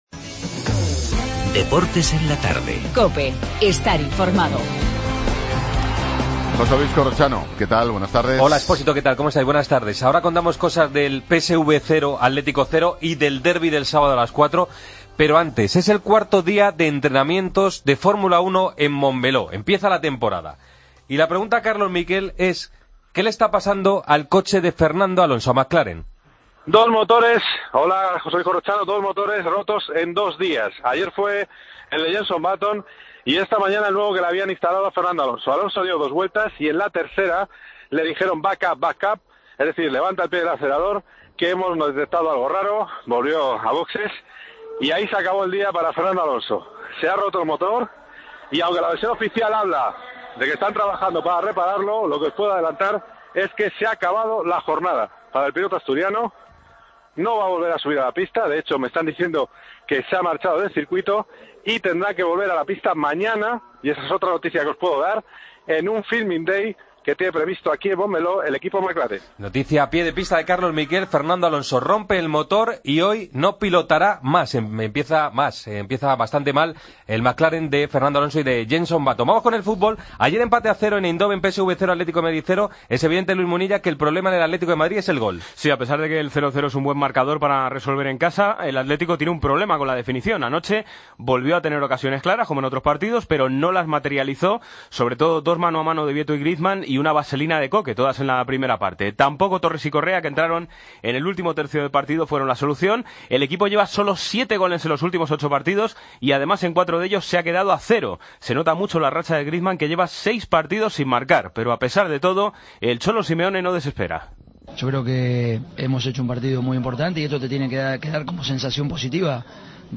Entrevista a Álvaro Pereira, jugador del Getafe.